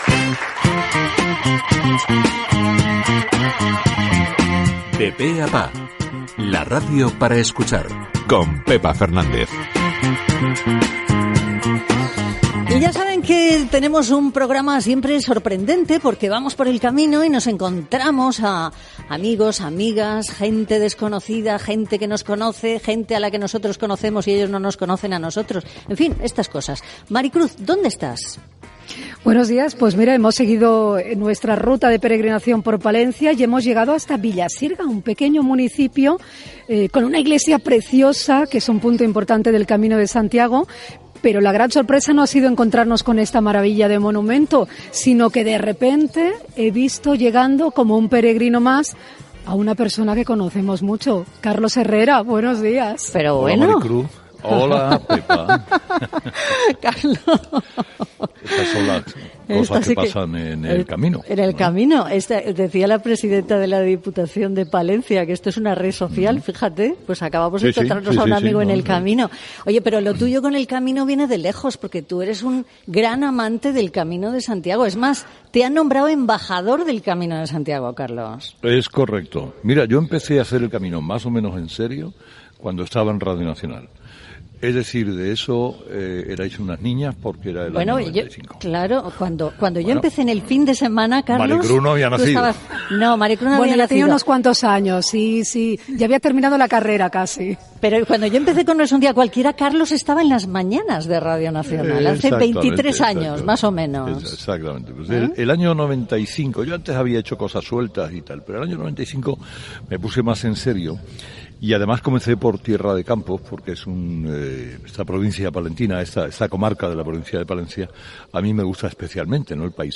Programa fet des de Frómista, a Palencia.
Entreteniment